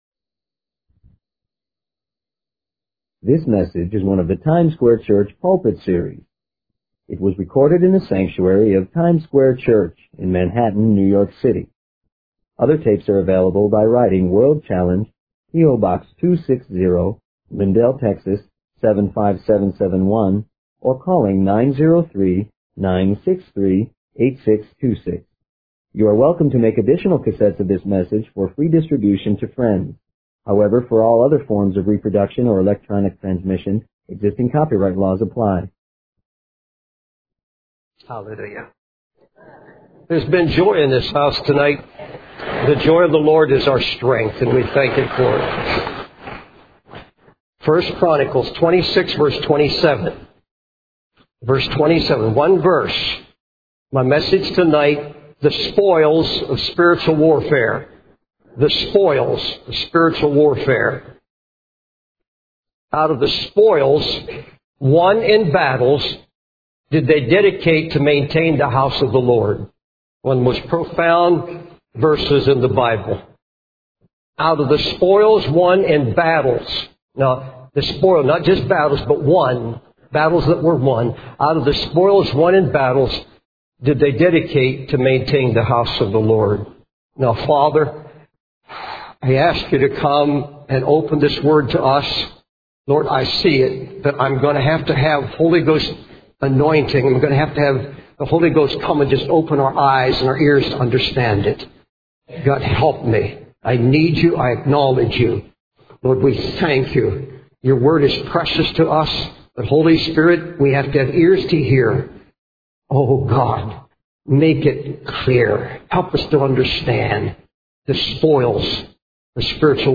In this sermon, the preacher discusses the spoils of spiritual warfare.